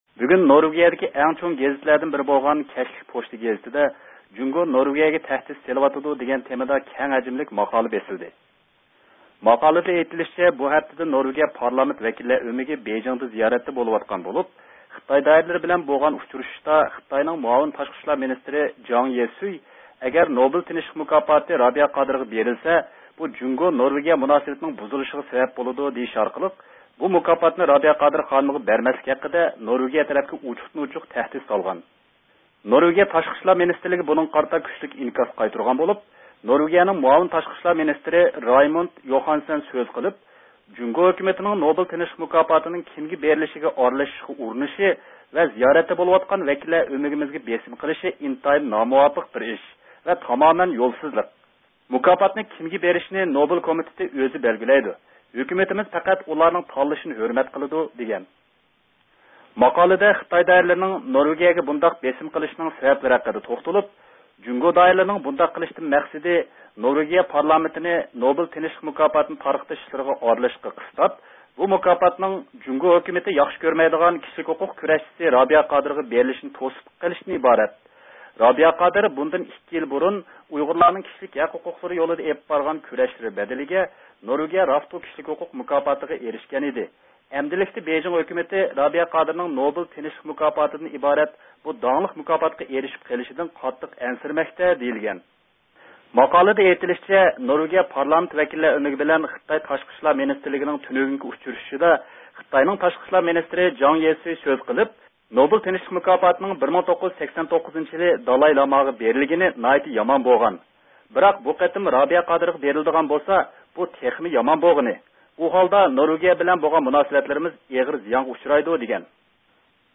بىز ئاخىردا يەنە بۇ ھەقتىكى ئىناكسىنى بىلىپ بېقىش ئۈچۈن ئۇيغۇر دېموكراتىيە ۋە كىشلىك ھوقۇق ھەرىكىتىنىڭ رەھبىرى، بۇ يىللىق نوبېل تىنچلىق مۇكاپاتىنىڭ نامزاتى رابىيە قادىر خانىمنى زىيارەت قىلدۇق: